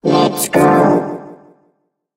evil_rick_start_vo_03.ogg